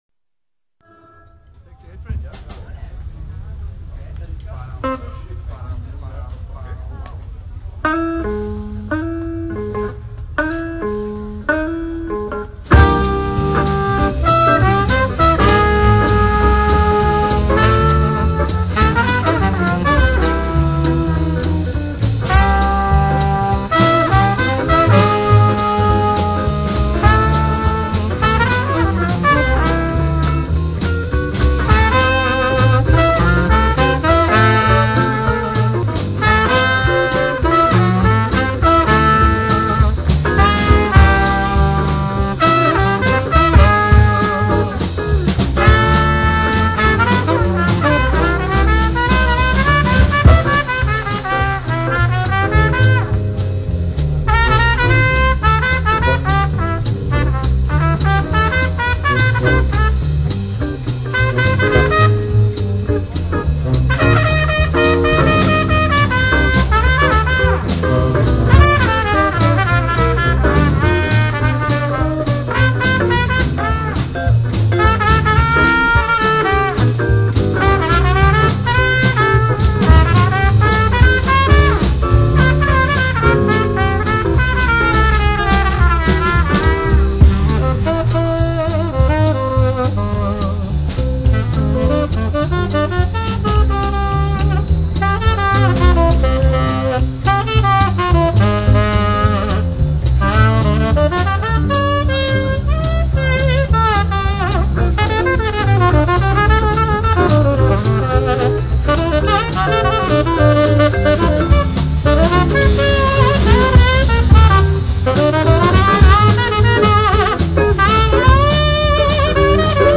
特制立体声录音